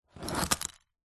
Звуки спичек, зажигалок
Горстка спичек поднята с ламинированного пола, вариант 1